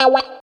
134 GTR 1 -L.wav